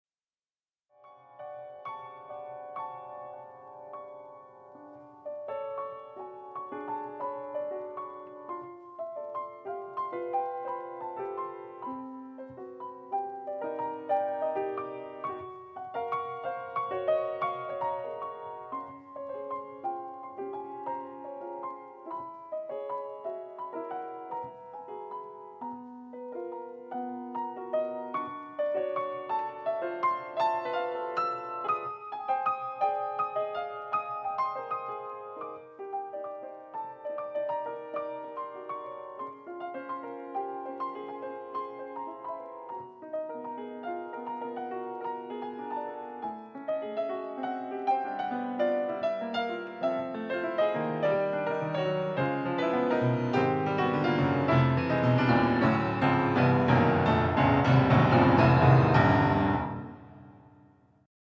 piano
Essentially a piano sonata in three short movements